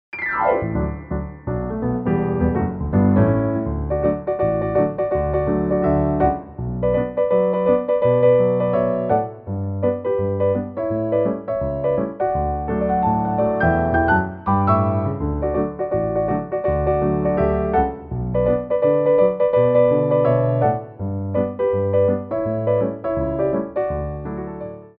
Petit Allegro 2
4/4 (8x8)